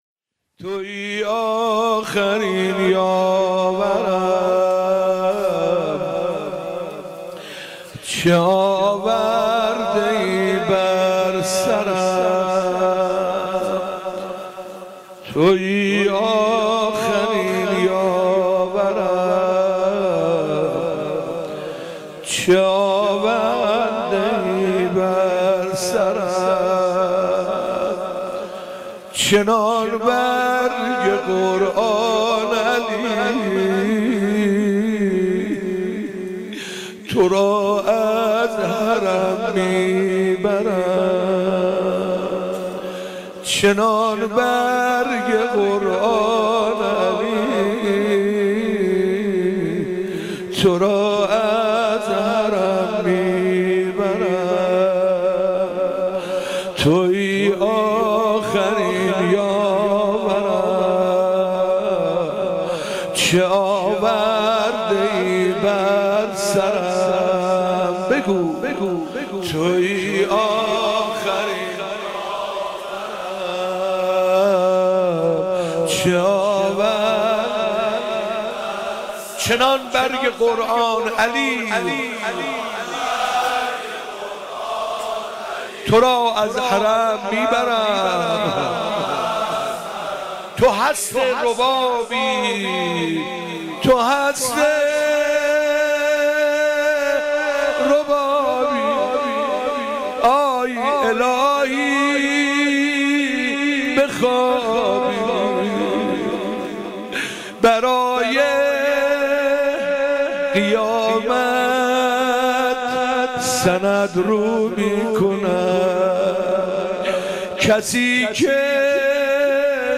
نوحه - تویی آخرین یاورم